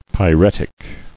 [py·ret·ic]